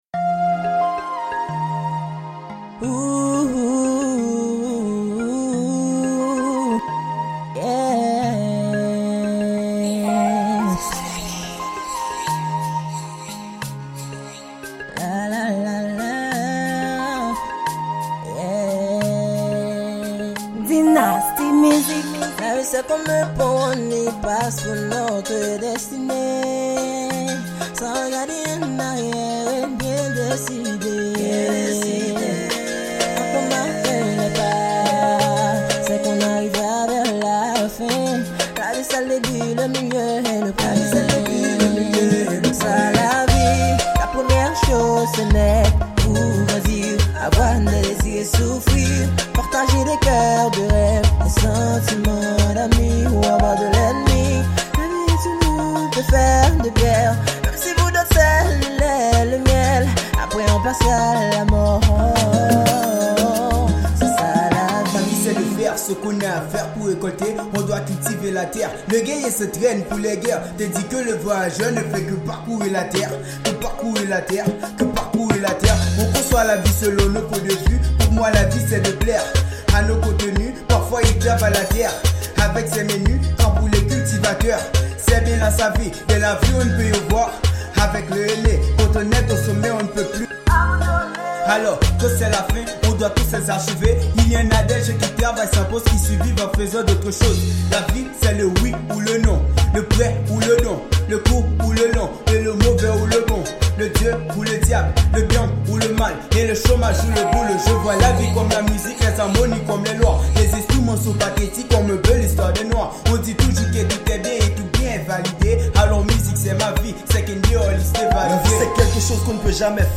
Genre: Zouk